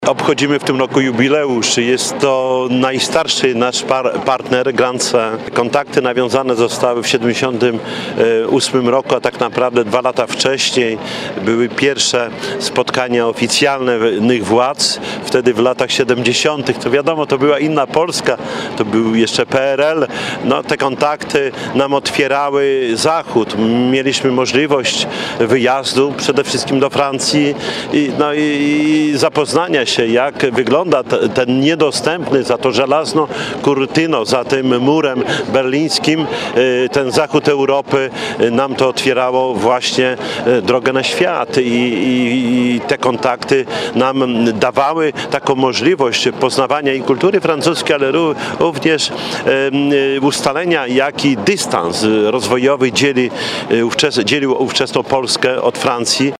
Głos zabrali Czesław Renkiewicz, prezydent Suwałk i mer Grande-Synthe, Damien Careme. Jak mówił w swoim przemówieniu Czesław Renkiewicz, w czasach PRL-u zachód Europy był dla nas praktycznie niedostępny, a dzięki tej współpracy mogliśmy porównać, jaki dystans rozwojowy dzielił Polskę od Francji.